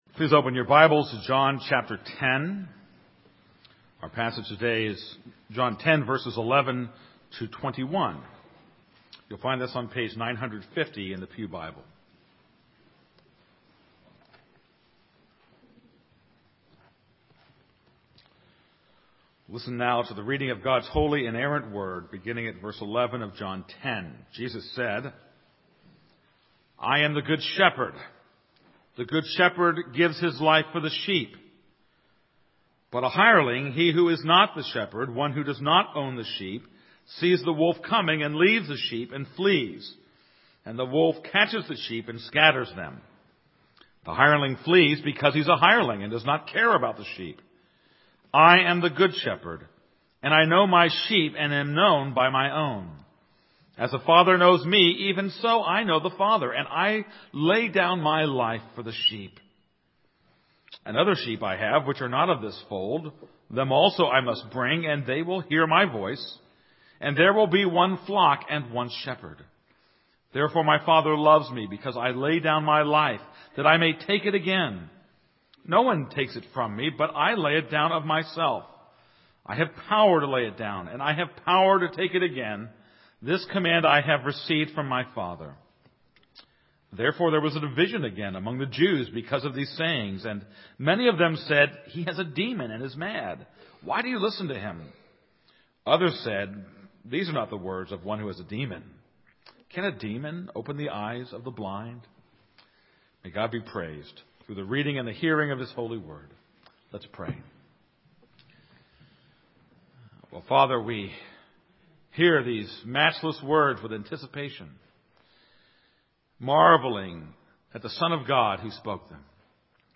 This is a sermon on John 10:11-21.